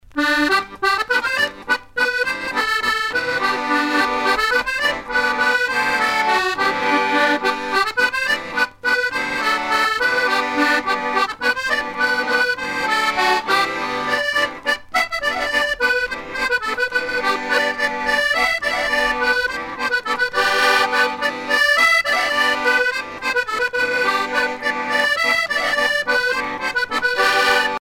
Bourrée
danse : bourree
Pièce musicale éditée